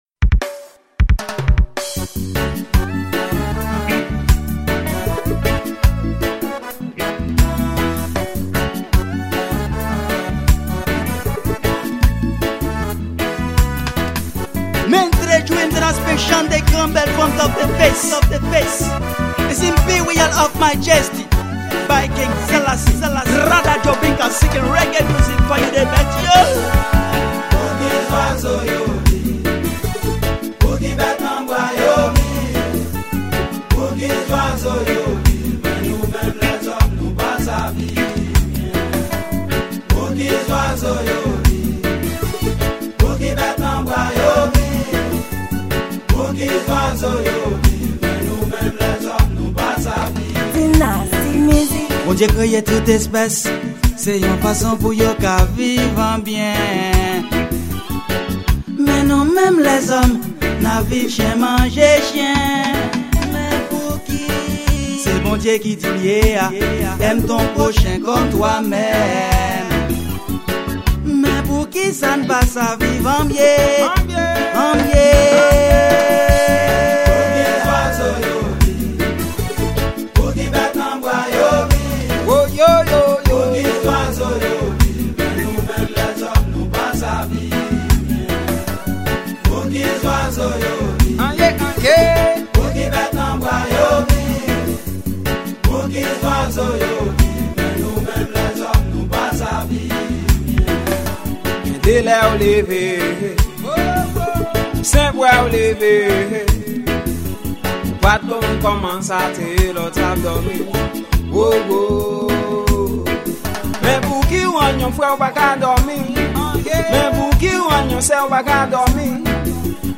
Genre: Raggae